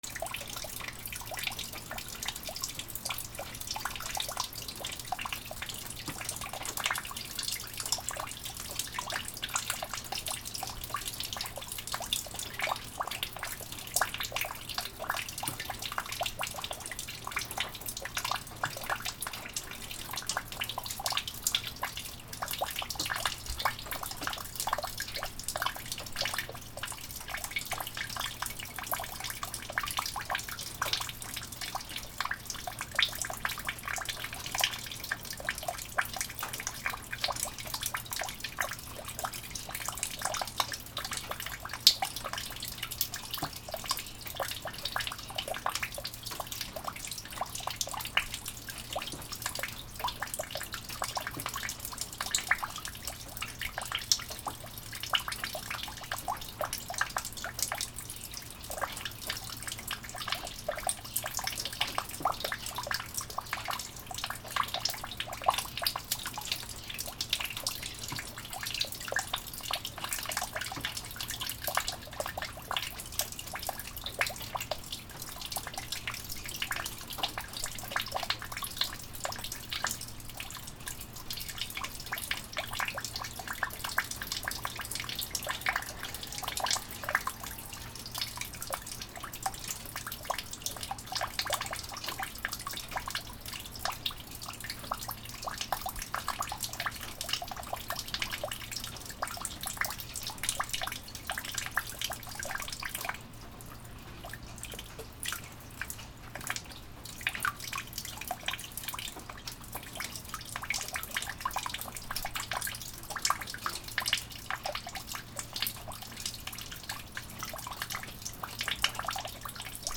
/ A｜環境音(天候) / A-25 ｜雨だれ
雨だれ 戸井から落ちる水音
ピチョピチョ